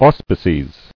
[aus·pi·ces]